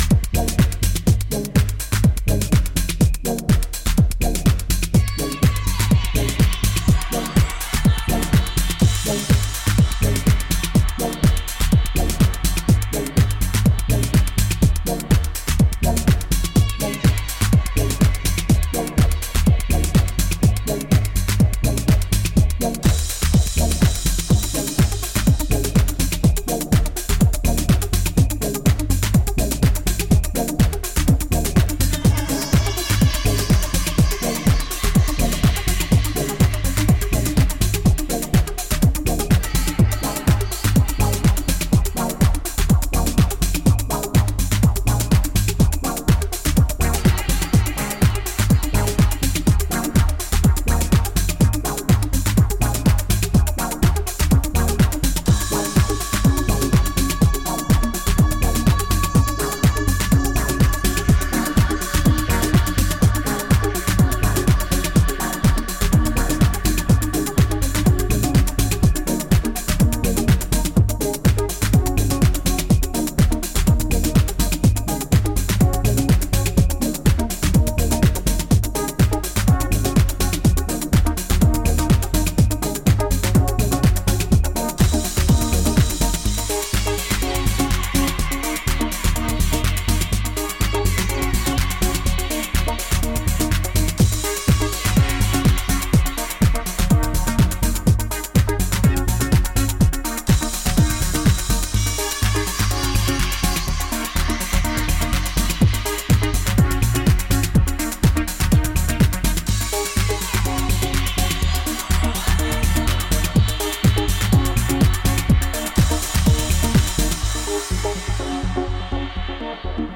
今回はダビーなフィーリングを纏いながらタイトでミニマルなディープ・ハウスを展開。